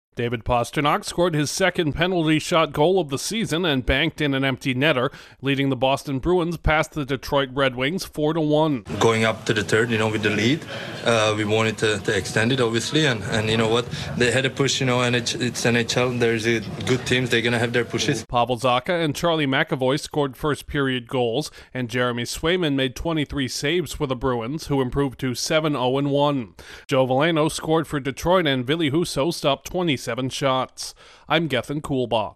The Bruins bounce back from their only loss of the season. Correspondent